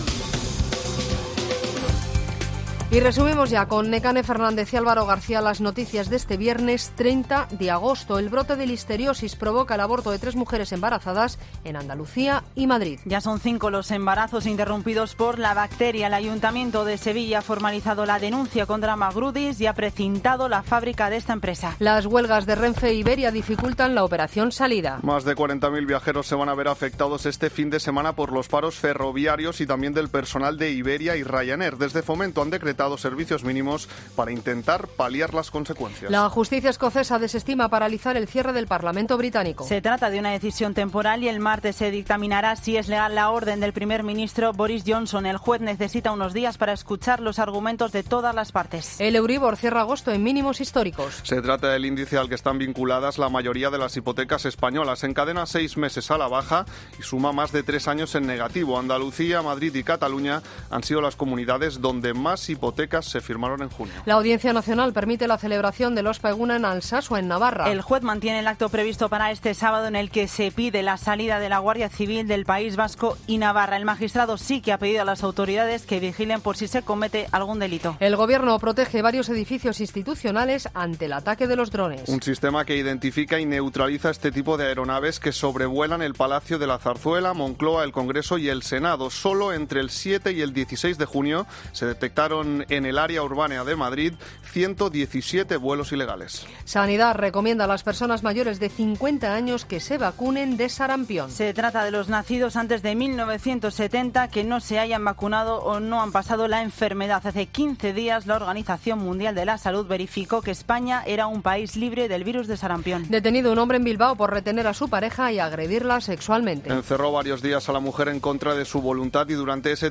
Boletín de noticias COPE del 30 de agosto a las 20.00